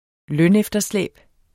Udtale [ ˈlœn- ]